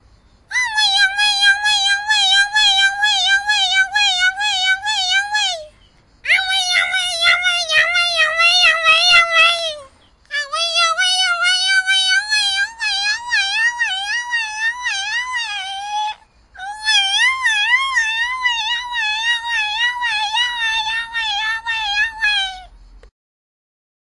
动物叫声 " 狗叫声 5
描述：狗的抱怨
标签： 动物 发牢骚 抱怨
声道立体声